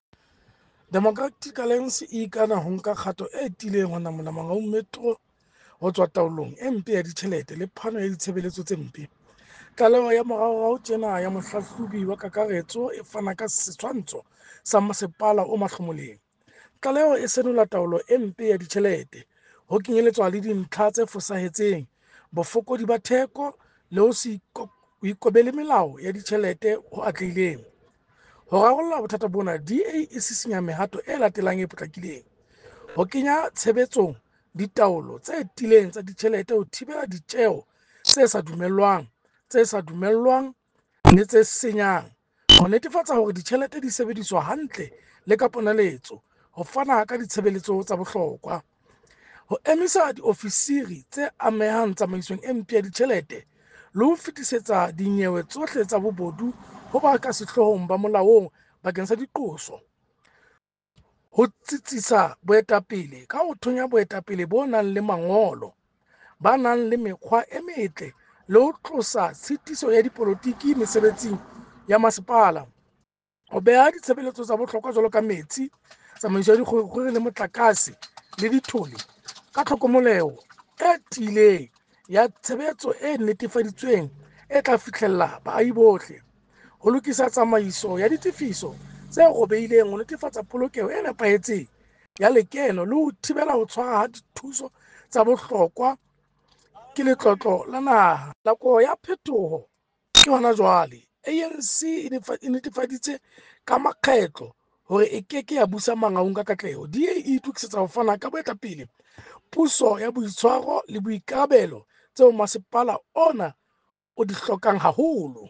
Sesotho soundbite by Cllr Kabelo Mooreng